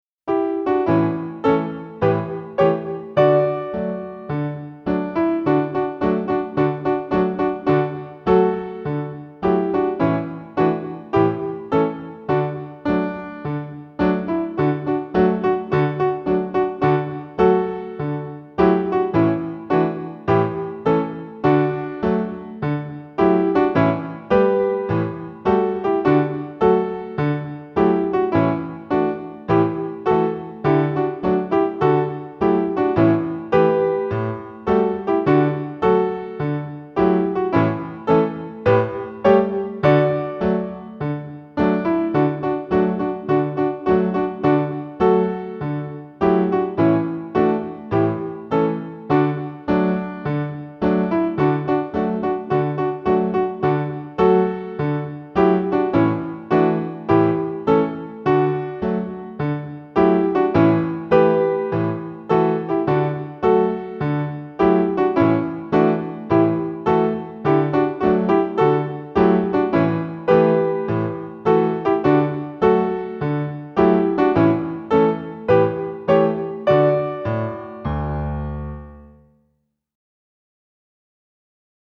Intermediate singing lessons for ages 6 and up